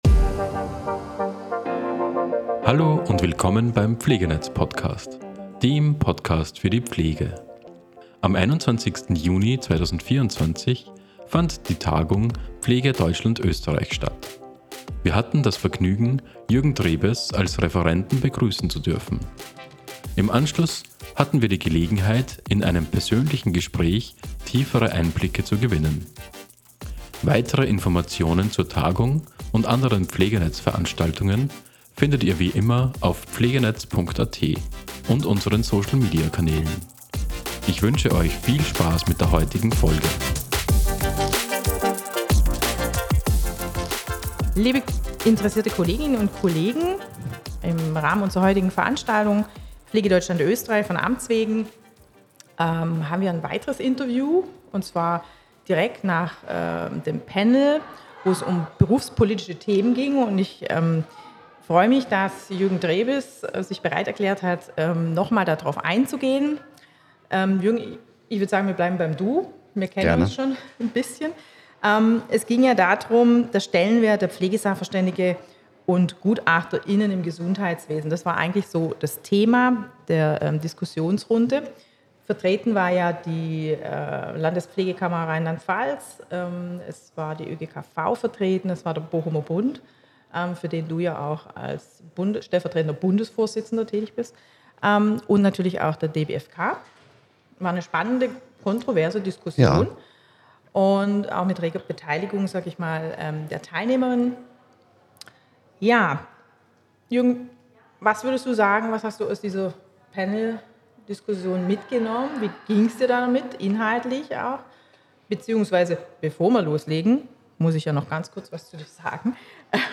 Tagung - pflege:deutschland:österreich